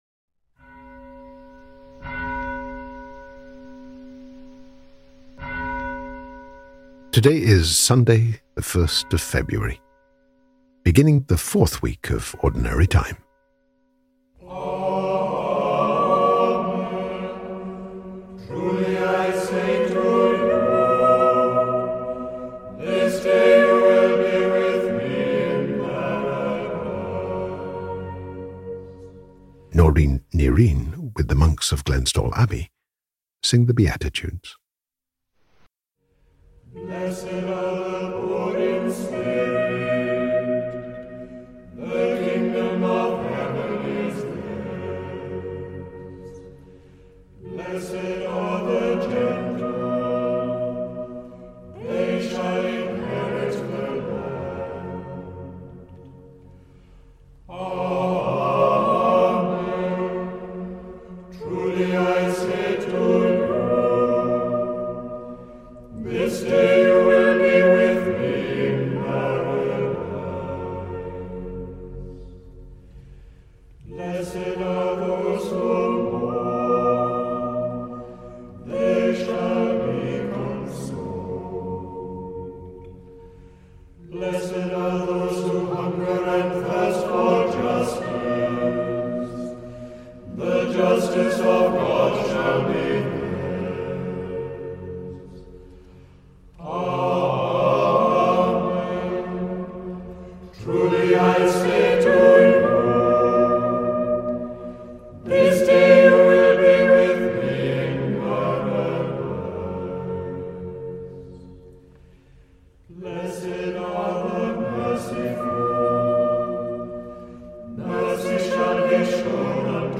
Nóirín Ní Riain, with the monks of Glenstal Abbey, sings the Beatitudes. Today’s reading is from the Gospel of Matthew.